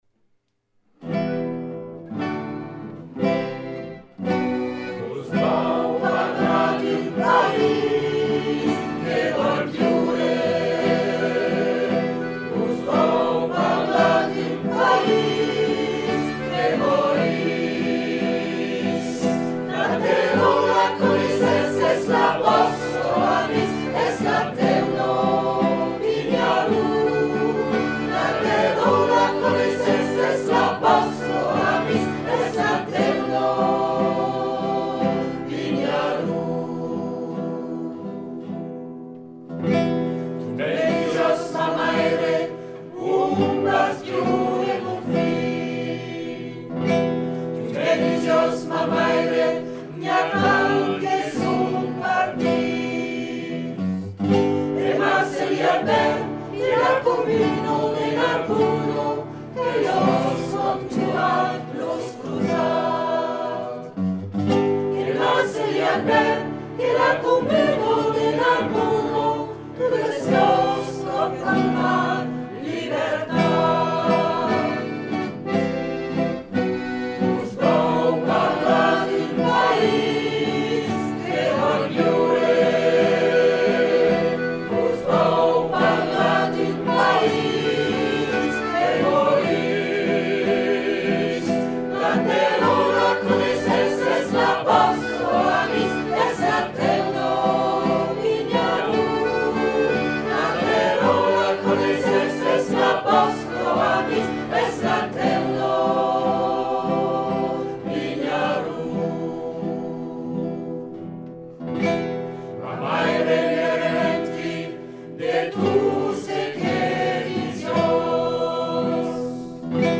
Pour vous, quelques titres chantés par Jacophonie en écoute sur le blog en attendant la reprise des ateliers et des concerts.